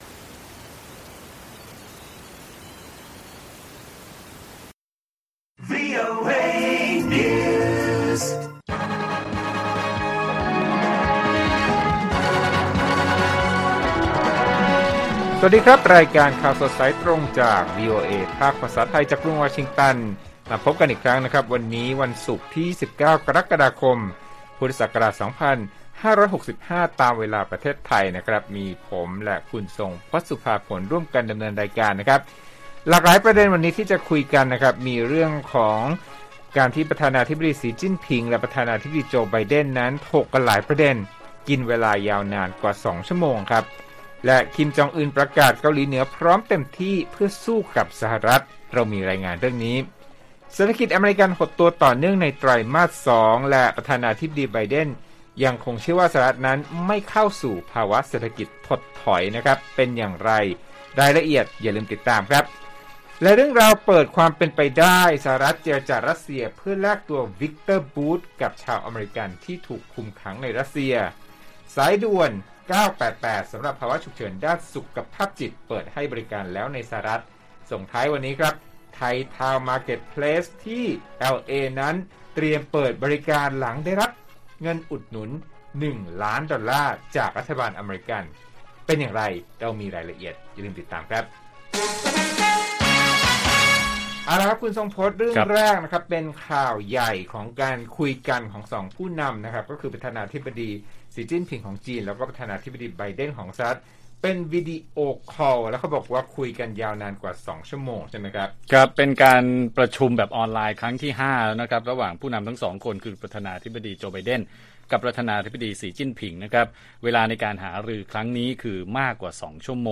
ข่าวสดสายตรงจากวีโอเอไทย 6:30 – 7:00 น. วันที่ 29 ก.ค. 65